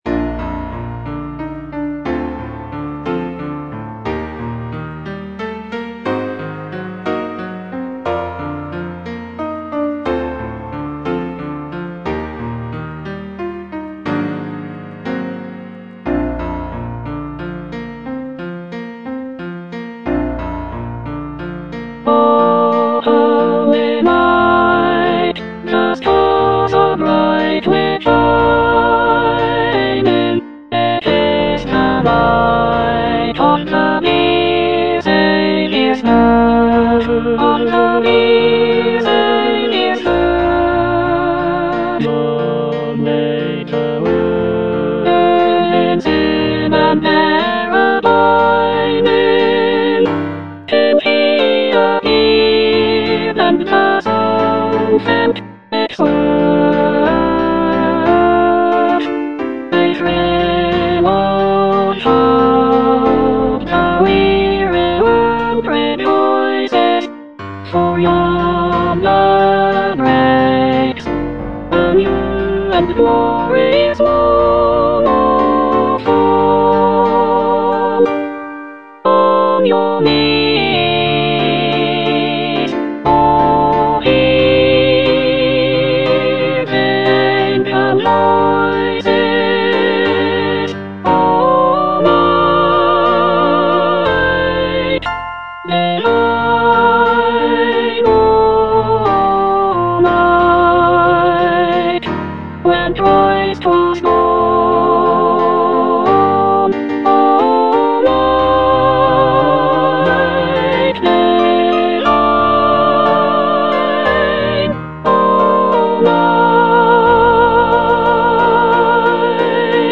Soprano II (Emphasised voice and other voices)